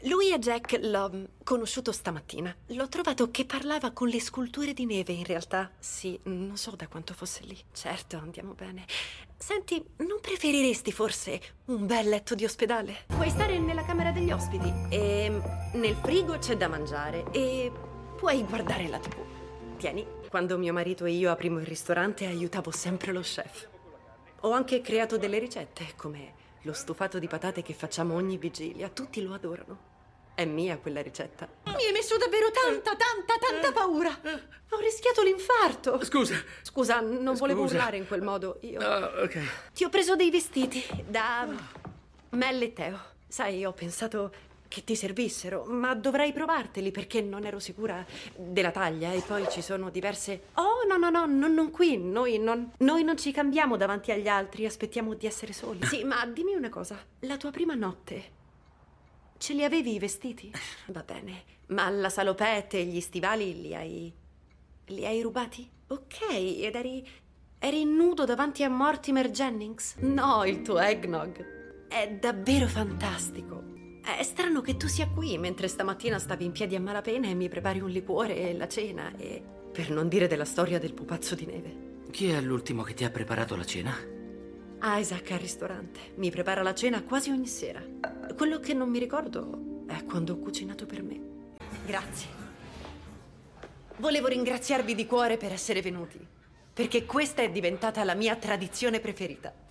nel film TV "Hot Frosty - Magia di Natale", in cui doppia Lacey Chabert.